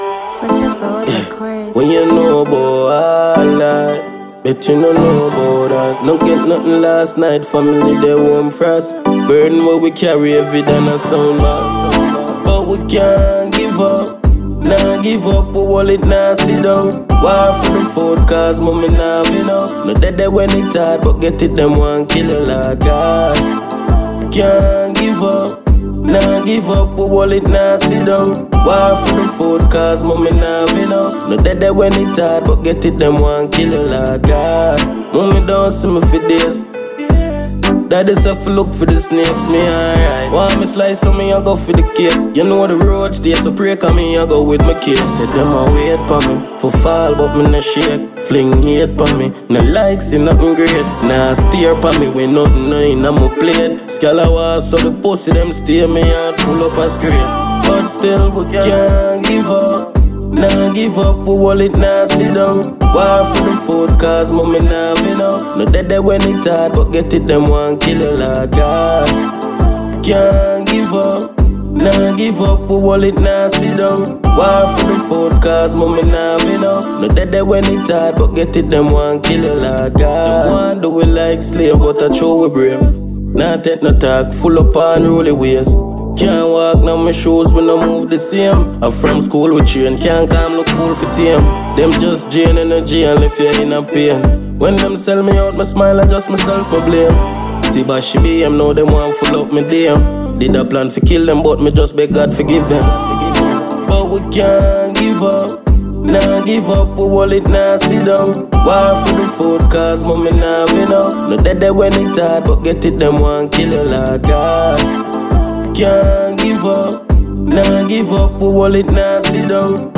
Dancehall/HiphopMusic
dancehall